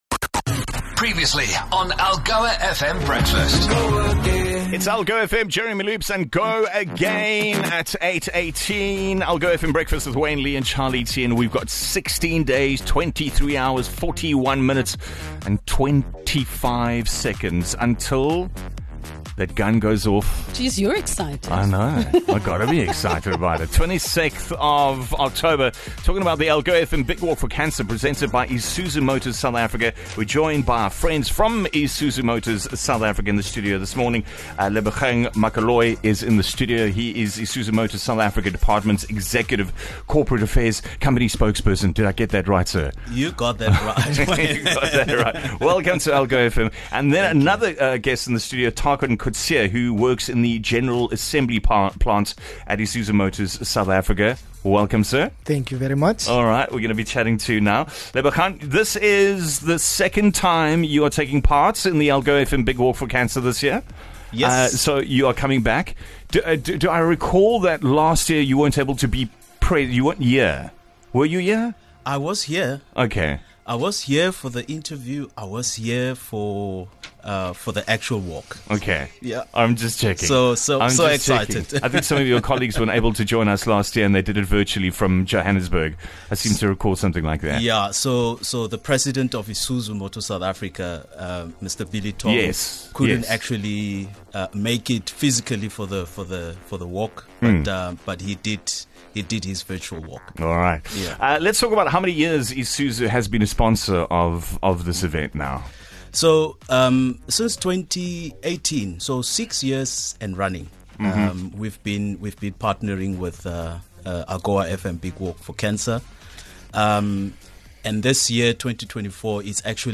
The countdown to the Algoa FM Big Walk for Cancer presented by ISUZU MOTORS SOUTH AFRICA has begun. In studio